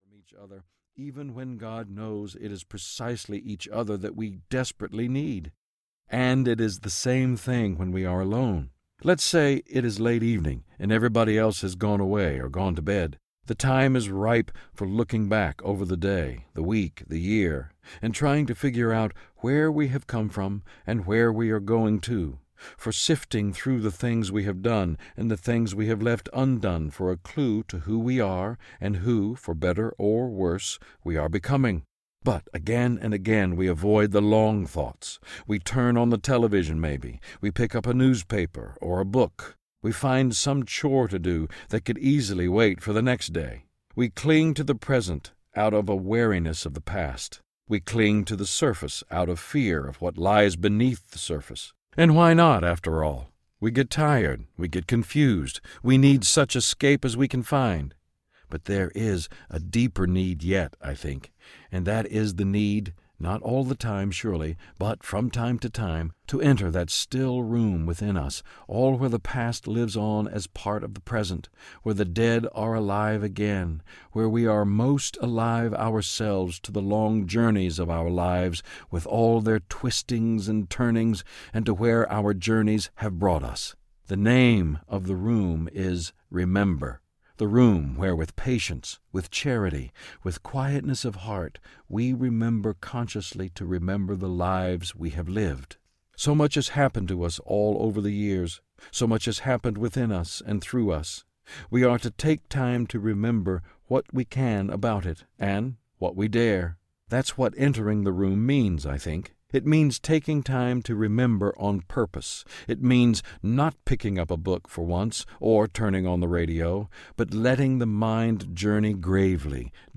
A Crazy, Holy Grace: The Healing Power of Pain and Memory Audiobook
Narrator
4.27 Hrs. – Unabridged